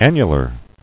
[an·nu·lar]